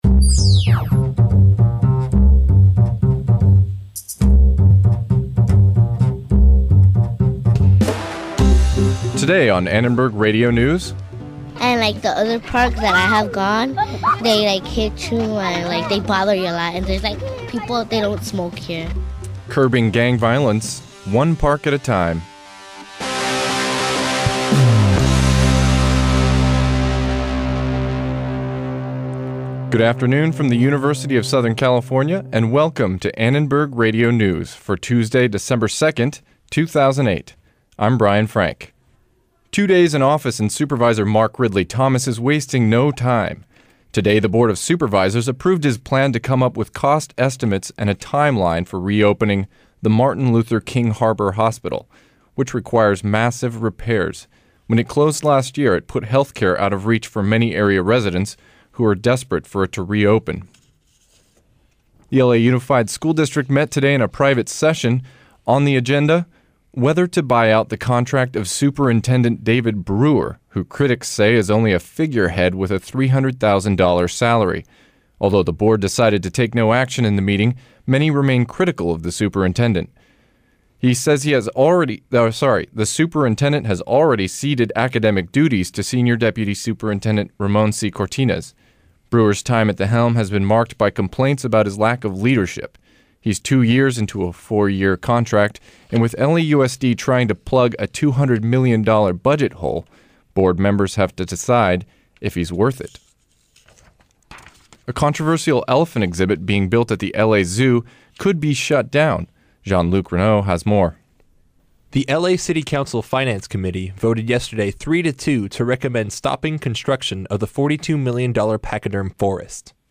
ARN Live Show - December 2, 2008 | USC Annenberg Radio News